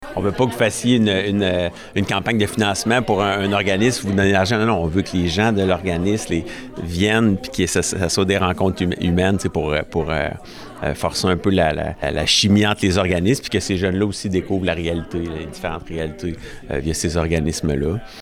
Lors d’une conférence de presse dans les locaux de la Maison des jeunes le Déclic mardi
Le conseiller municipal responsable du comité jeunesse, Dominic Massé, a indiqué que des activités de financement sont en vue et que ce sera l’occasion pour les jeunes de découvrir divers partenaires liés à la jeunesse.